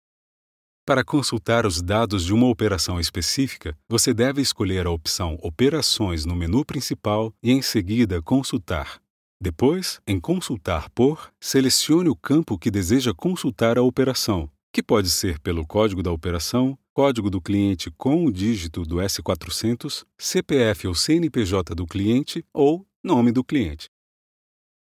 Apprentissage en ligne
Ma voix est naturelle et professionnelle. Elle est souvent décrite comme crédible, veloutée et douce, inspirant confiance et calme à l'auditeur.
Microphone : Neumann TLM103
Cabine vocale acoustiquement isolée et traitée
BarytonBasseProfondBas